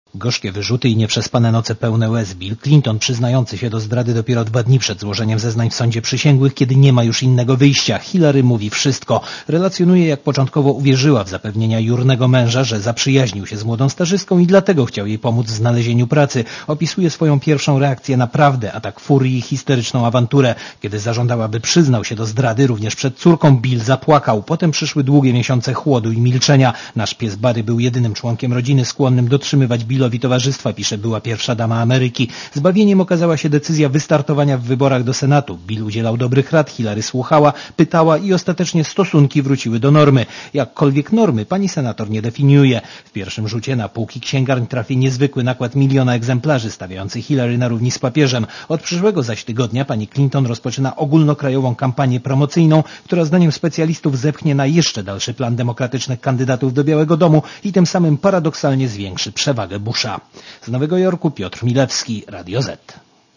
Posłuchaj relacji korespondenta Radia Zet z Nowego Jorku (246 KB)